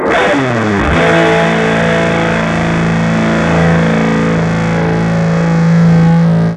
gtdTTE67027guitar-A.wav